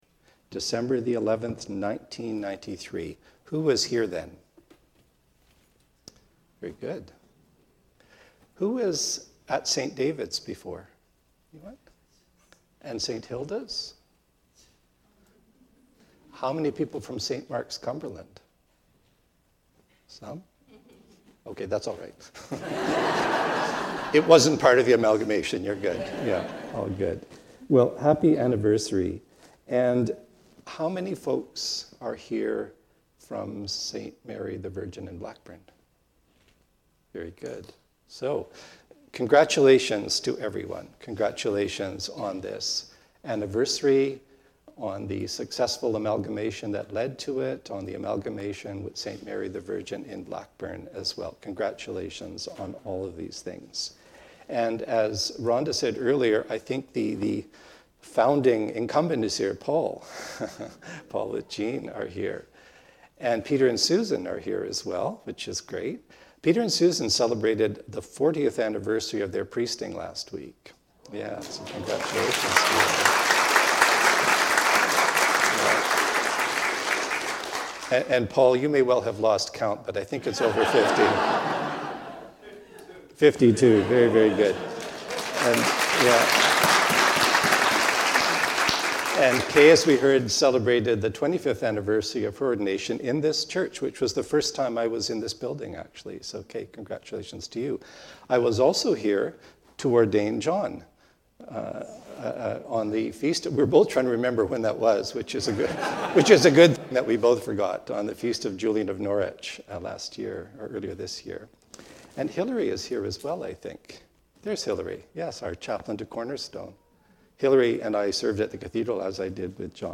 Are we there yet? A sermon for the 2nd Sunday of Advent.
And on the occasion of the 30th anniversary of worship in St. Helen’s church building.